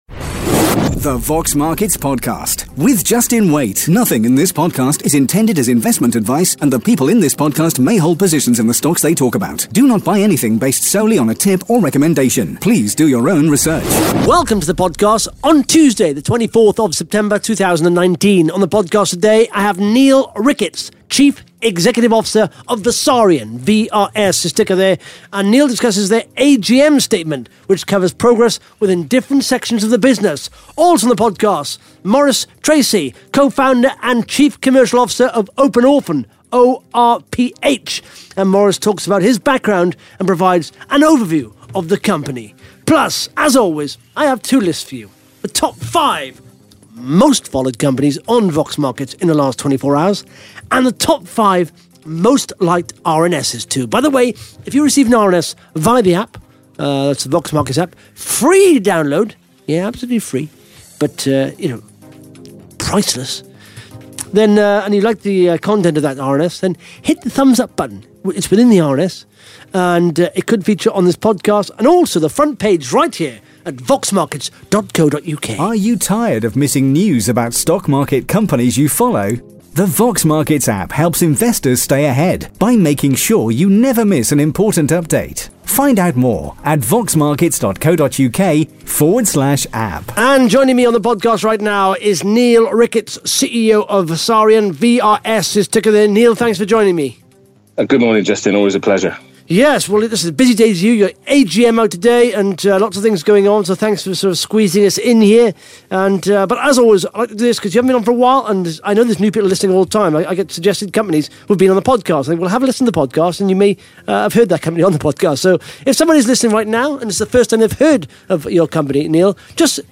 (Interview starts at 10 minutes) Plus the Top 5 Most Followed Companies & the Top 5 Most Liked RNS’s on Vox Markets in the last 24 hours.